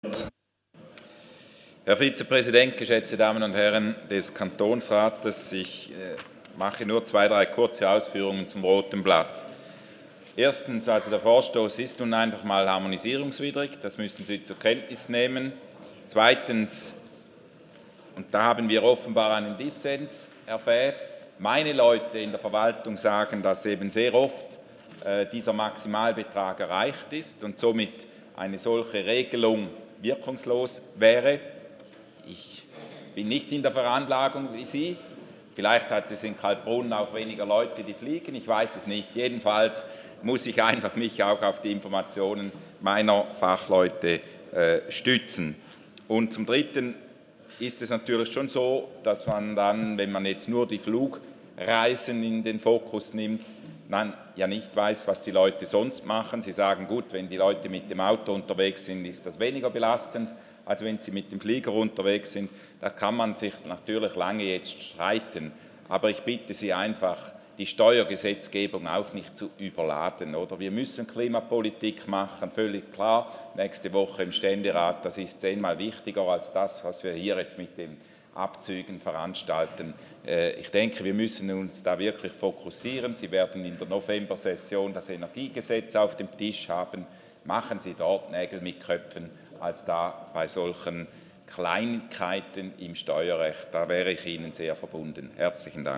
17.9.2019Wortmeldung
Session des Kantonsrates vom 16. bis 18. September 2019